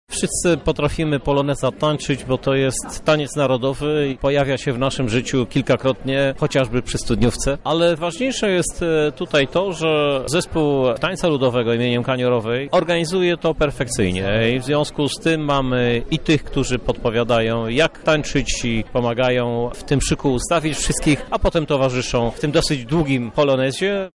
Polonez z jednej strony jest radosnym świętowaniem, z drugiej natomiast formą szacunku dla narodowego tańca – mówi prezydent.